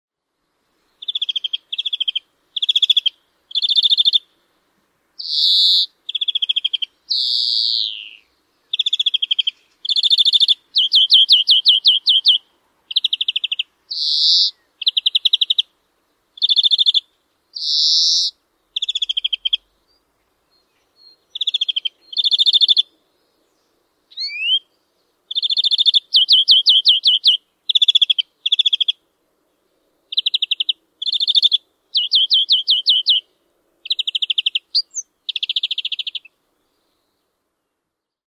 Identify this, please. Kuuntele: Viherpeippo